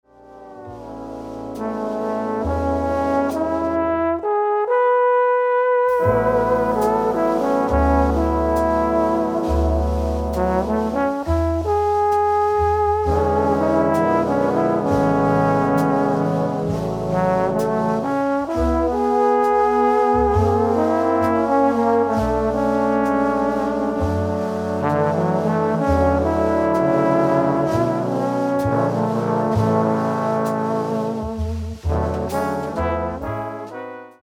For 6 trombones (4 tenor – 2 bass), piano, bass and drums
Medium difficulty (ballad feature for 1 trombone).